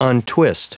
Prononciation du mot untwist en anglais (fichier audio)
Prononciation du mot : untwist
untwist.wav